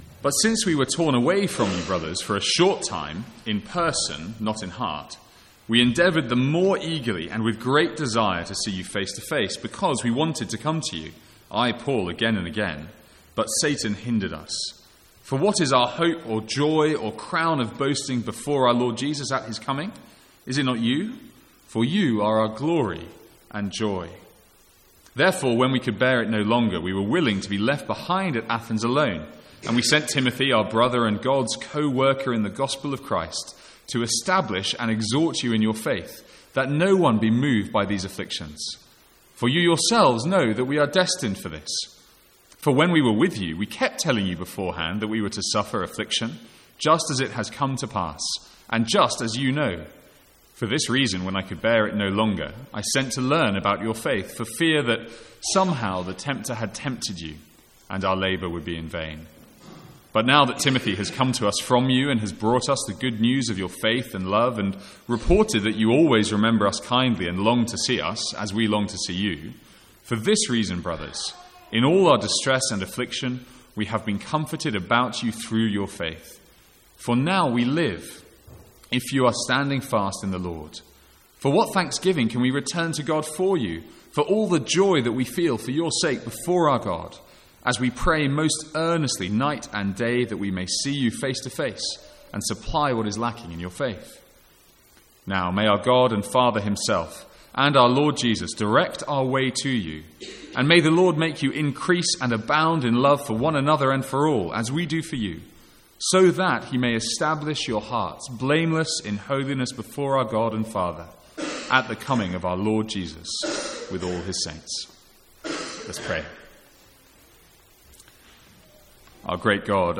Sermons | St Andrews Free Church
From the Sunday evening series in 1 Thessalonians.